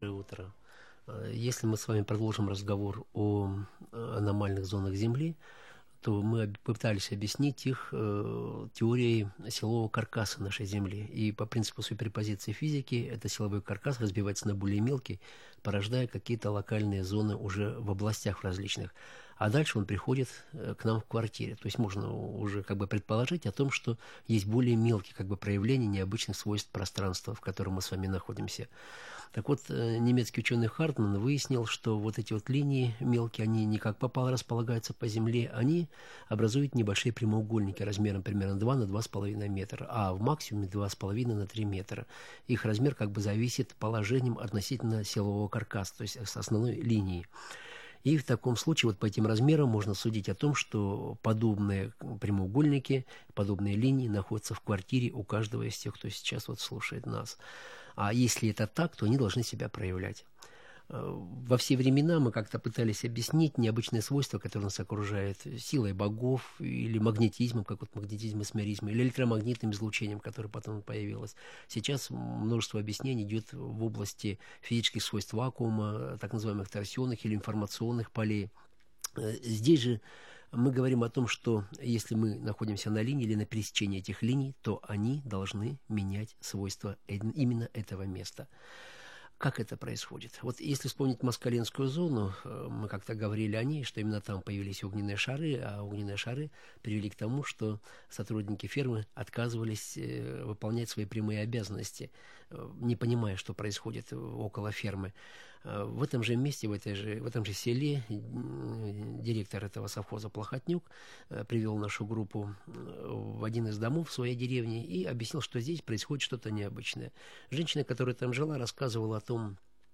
Аудиозапись шестой по счету программы из радиосериала Внеземные цивилизации.